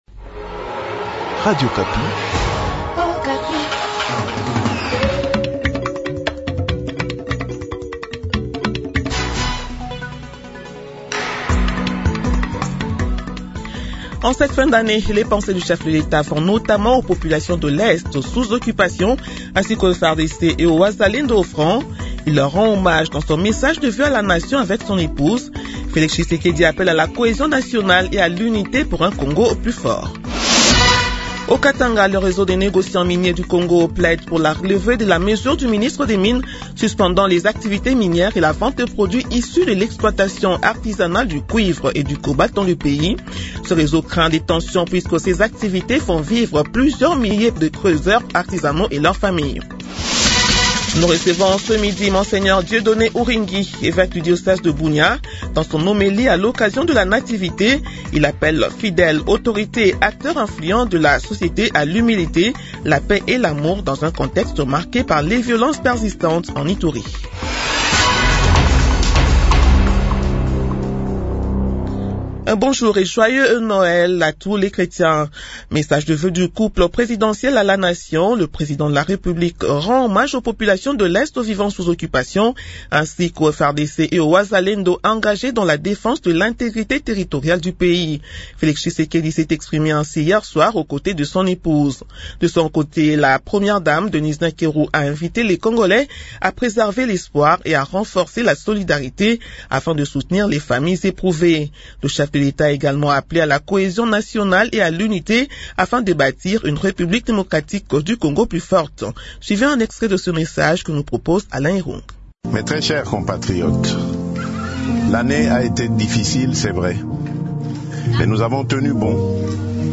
Journal de 12h du 25 décembre 2025